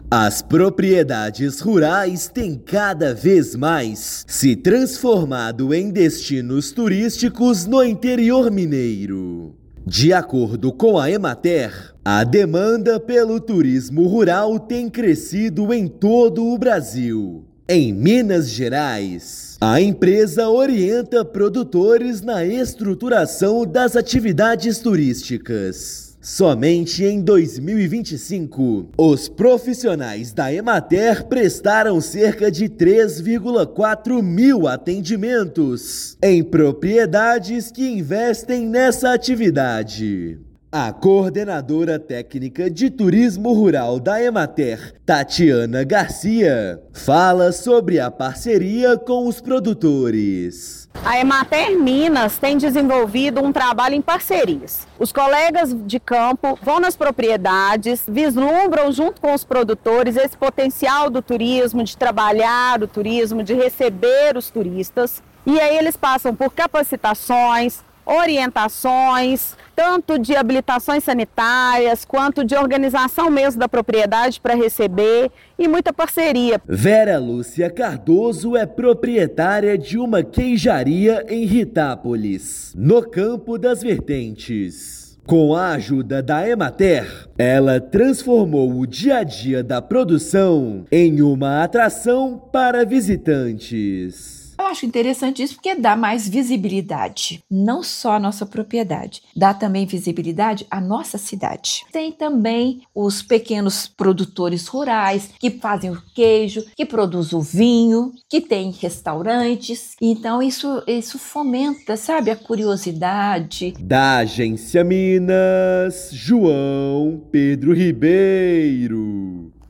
Atividade gera novas oportunidades de renda para pequenos produtores de Minas Gerais. Ouça matéria de rádio.